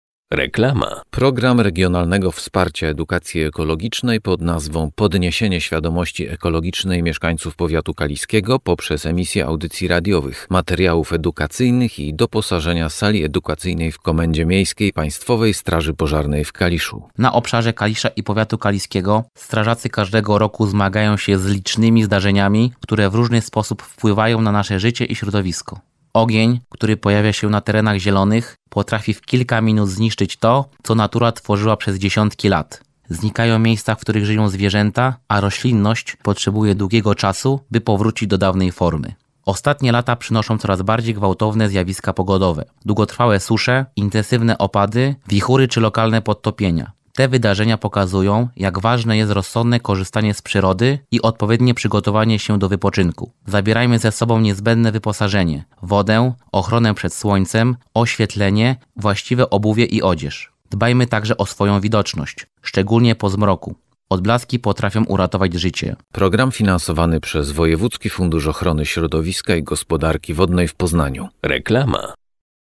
Audycja radiowa - " Chrońmy środowisko "